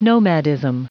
Prononciation du mot nomadism en anglais (fichier audio)